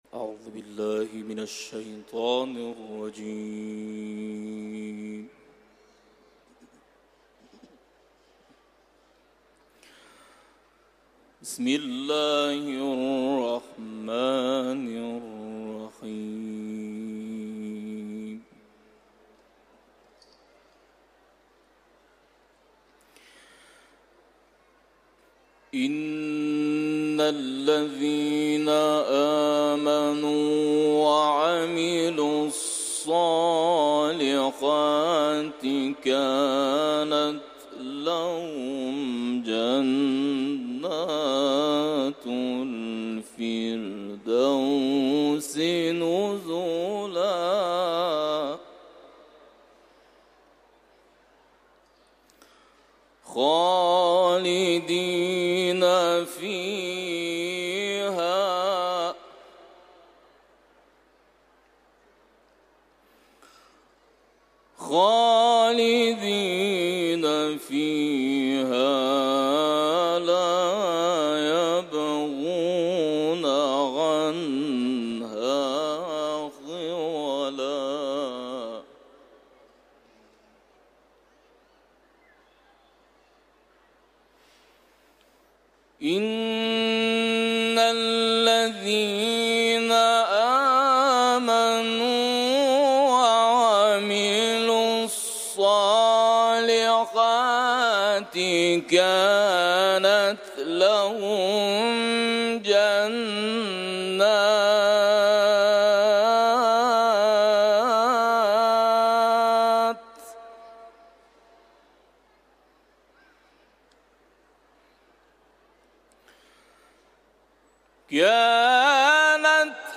Etiketler: İranlı kâri ، Kehf suresi ، Kuran tilaveti